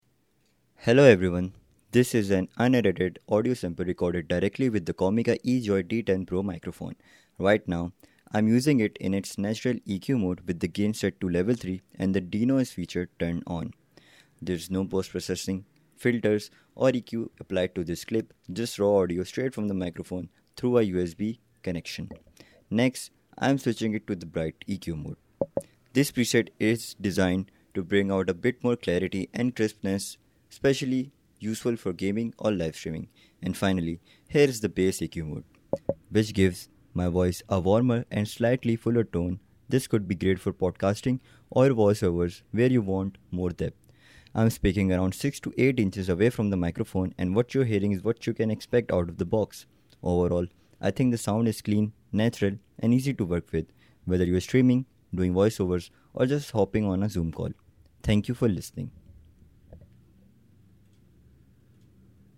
Moreover, it utilizes a large diaphragm dynamic capsule, which lends the voice a nice depth.
It records at 48 kHz/24-bit, and you can hear the difference.
The audio quality is crisp and detailed, and the denoise feature works superbly; it is not just a gimmick.
Comica-EJoy-D10-PRO-Audio-Sample.mp3